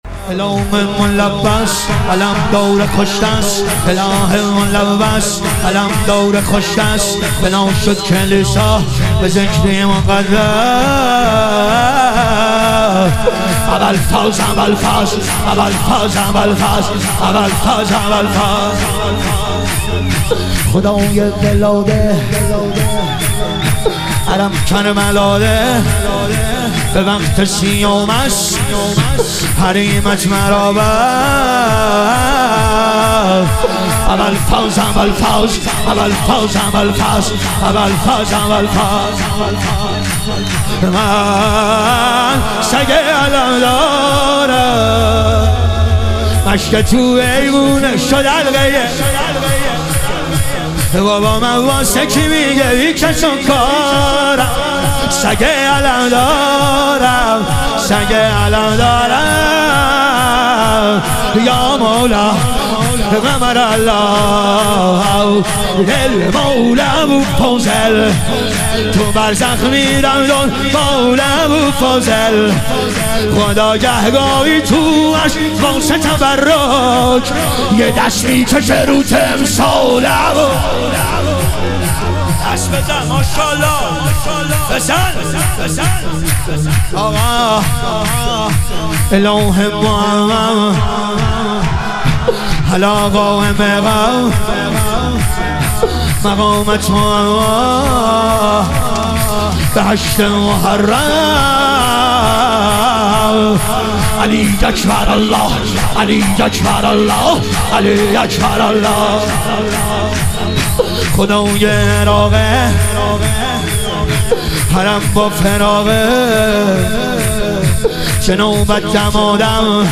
ظهور وجود مقدس امام سجاد علیه السلام - شور